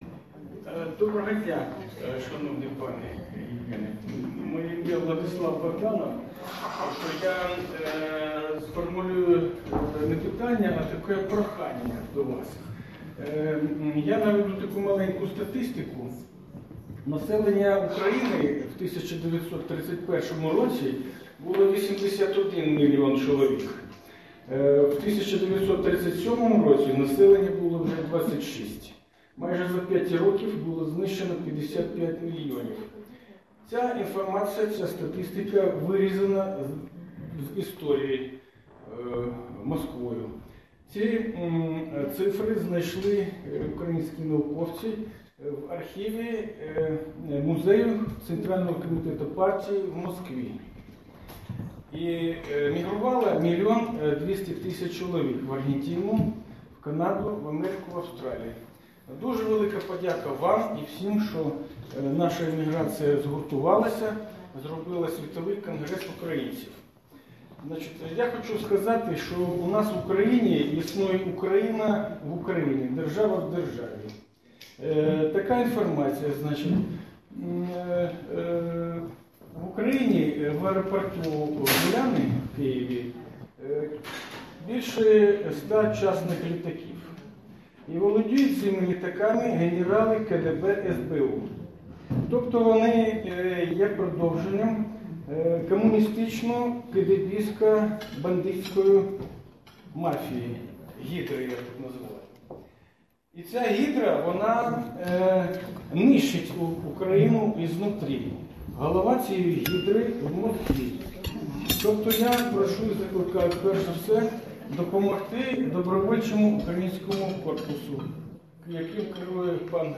Sydney - celebration of 50 years of the Ukrainian World Congress, and 70 years of Ukrainian Settlement in Australia.